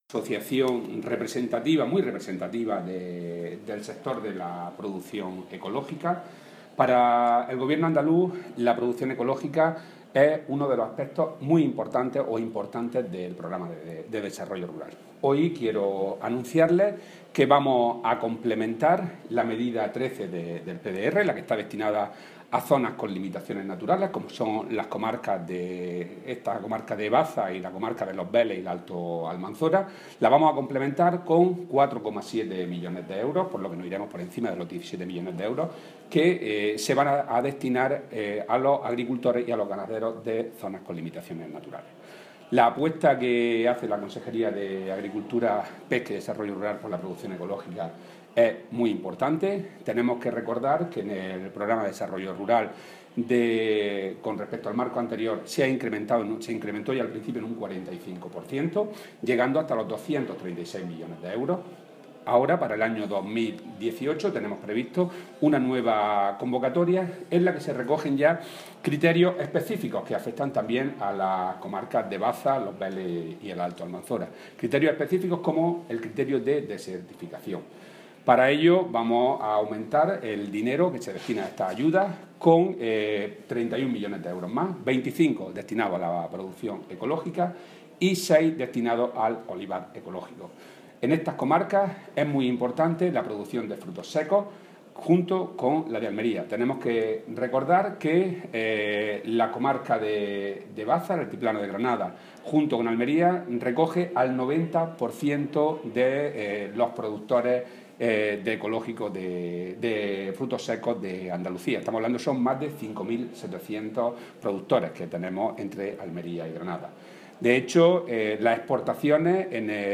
Declaraciones de Rodrigo Sánchez Haro sobre la asociación Alvelal y el apoyo de la Junta de Andalucía al sector ecológico (audio)